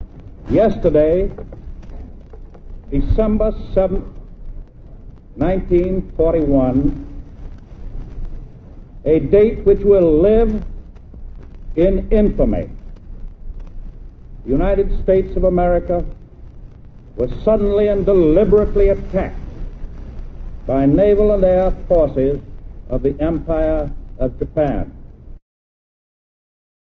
dayofinfamyspeech.mp3